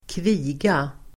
Ladda ner uttalet
Folkets service: kviga kviga substantiv, heifer Uttal: [²kv'i:ga] Böjningar: kvigan, kvigor Synonymer: ko, nötkreatur Definition: ko som ännu inte har kalvat heifer substantiv, kviga , ko som ännu inte har kalvat